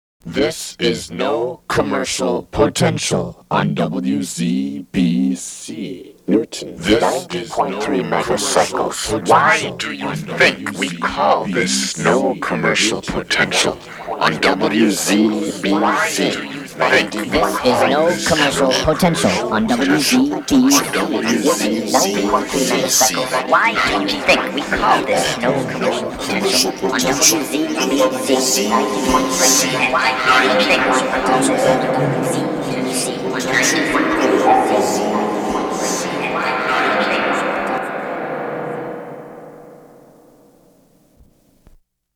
1982 NCP ID Legal ID